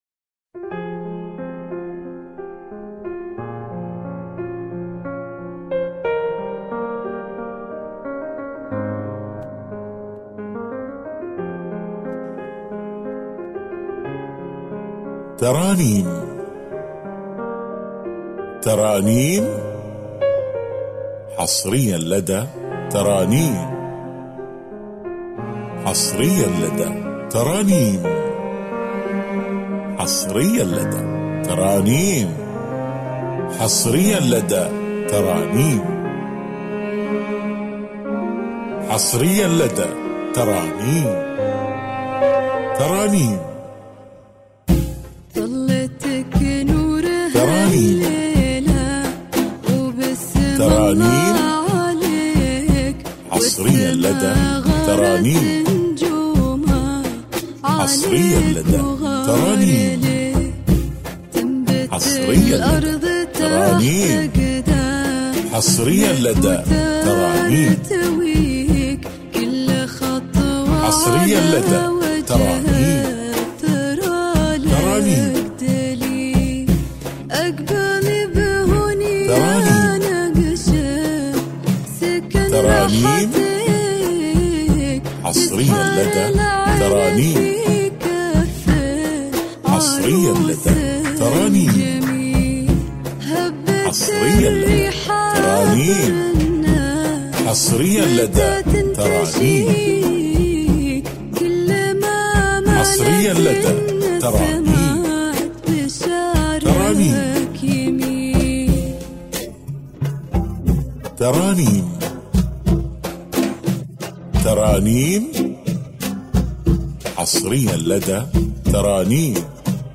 بدون موسيقى